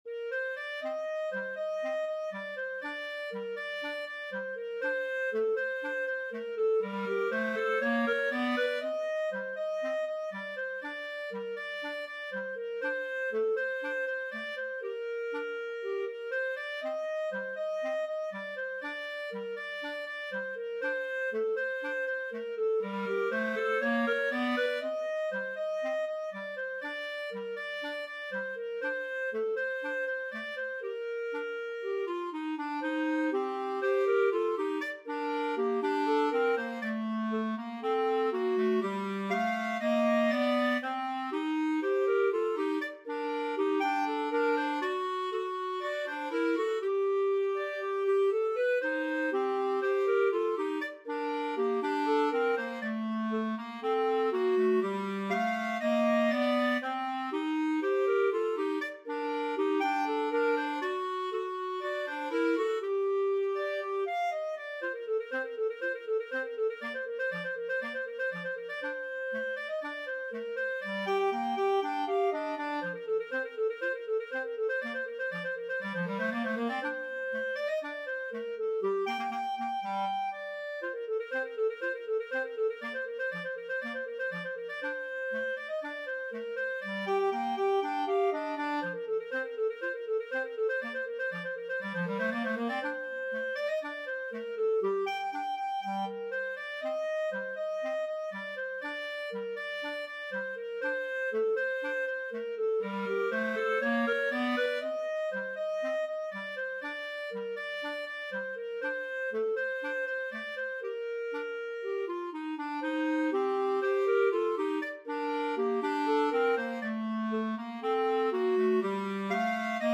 Free Sheet music for Clarinet Duet
2/2 (View more 2/2 Music)
G minor (Sounding Pitch) A minor (Clarinet in Bb) (View more G minor Music for Clarinet Duet )
Fast Two in a Bar =c.120
Traditional (View more Traditional Clarinet Duet Music)